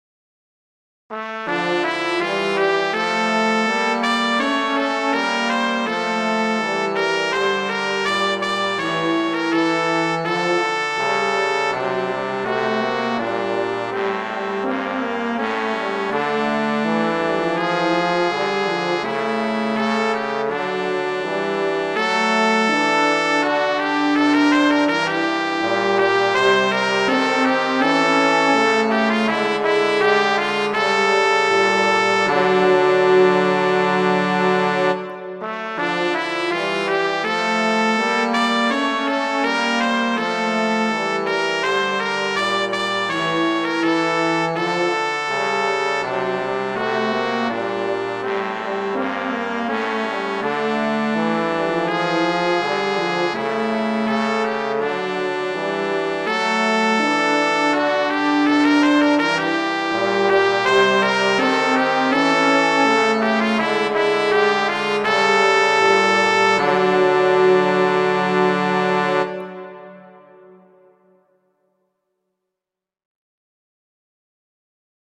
horn in F:
trombone: